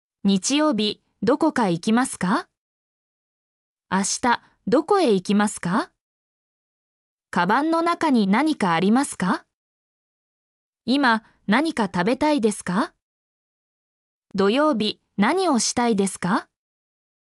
mp3-output-ttsfreedotcom-12_yeLPwaN9.mp3